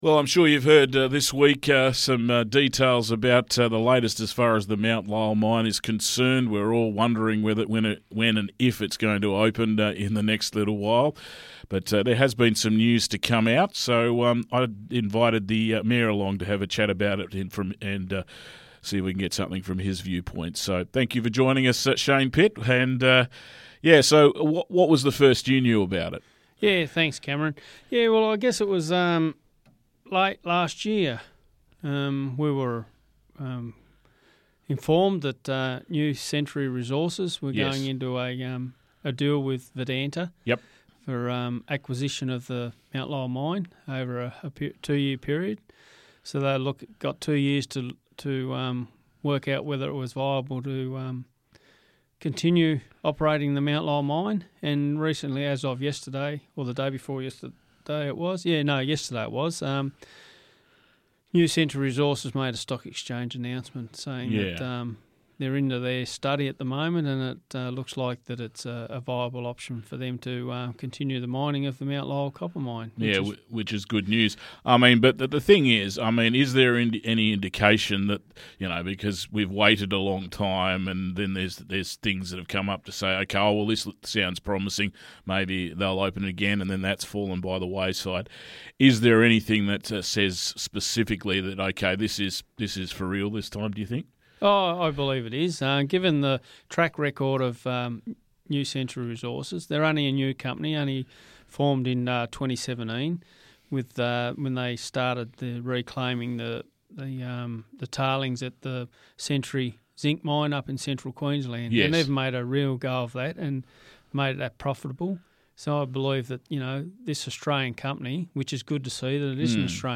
Interview with Mayor Shane Pitt about the Mount Lyell Mine